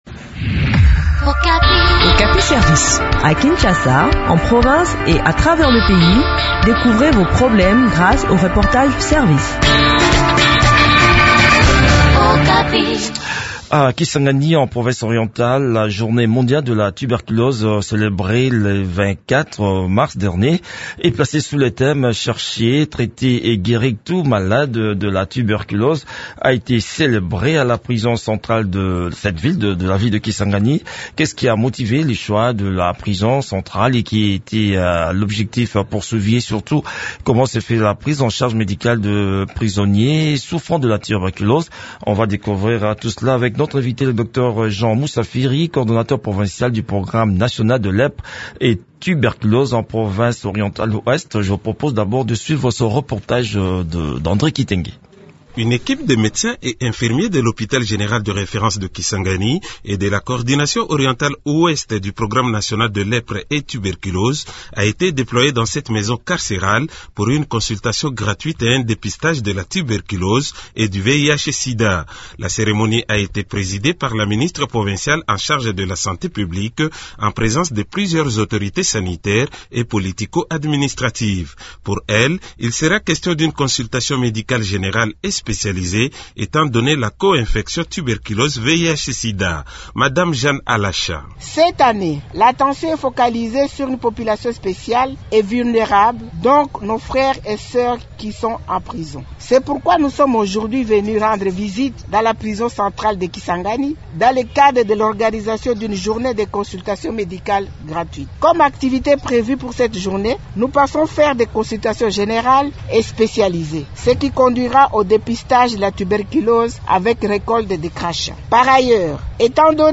Le point sur le déroulement de cette activité dans cet entretien